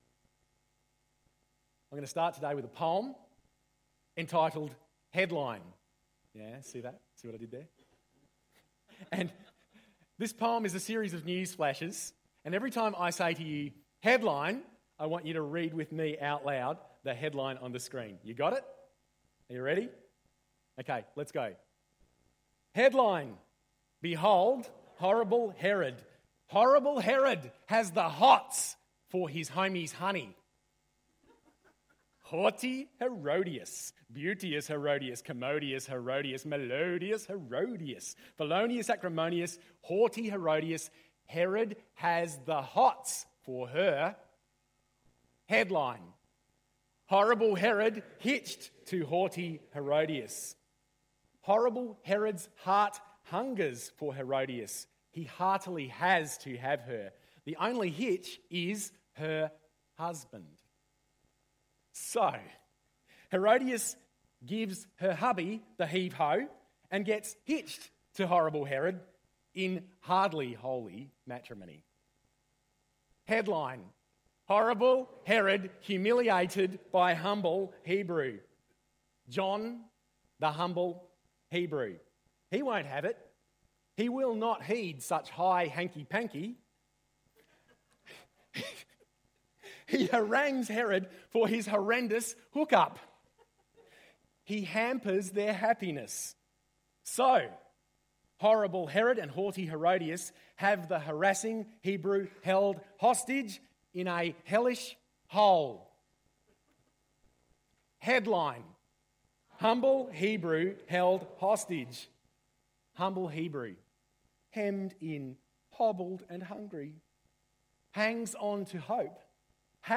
The blackboard was used a lot for this sermon so you miss the visuals in the sound recording.